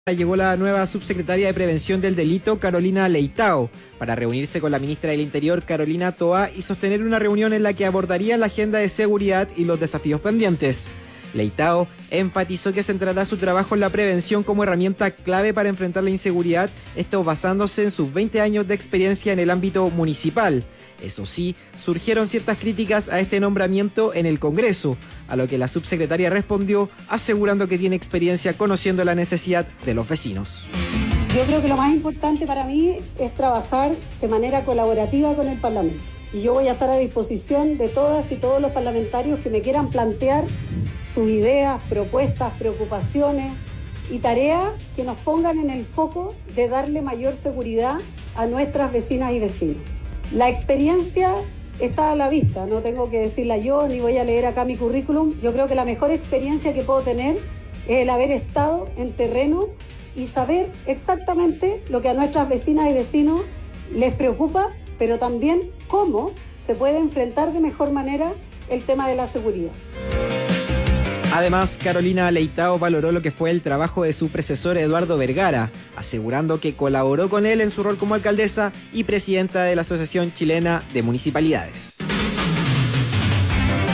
En su primera intervención como subsecretaria, en el Palacio de La Moneda, Leitao destacó la relevancia de la prevención en el abordaje del crimen.